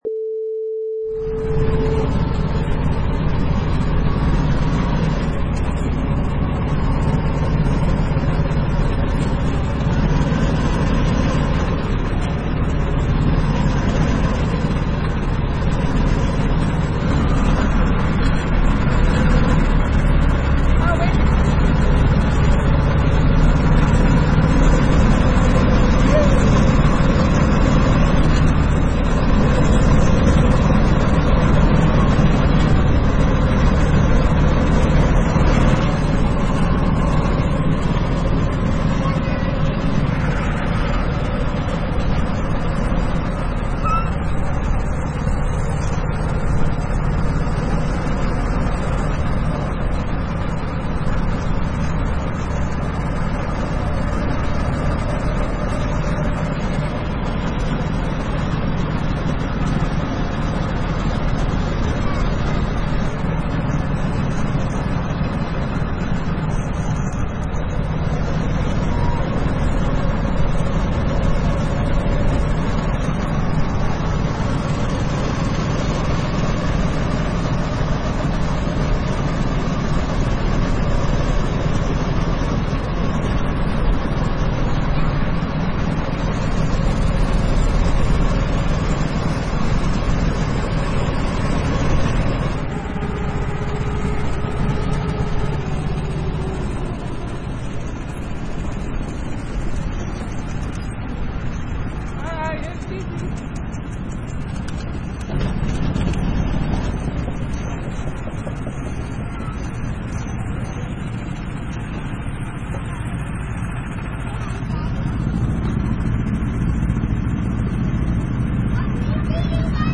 Go-Kart Idles
Product Info: 48k 24bit Stereo
Category: Vehicles / Go-Karts
Try preview above (pink tone added for copyright).
Go-Kart_Idle_2.mp3